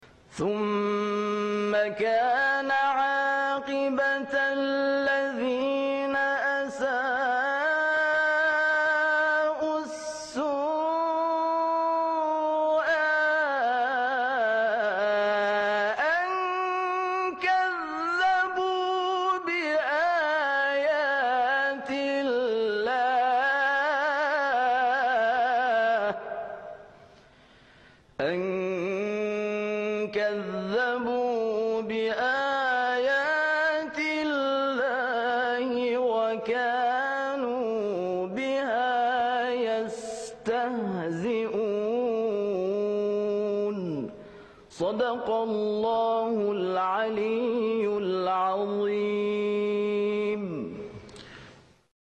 صوت | تلاوت آیه 10«سوره روم» با صوت قاریان شهیر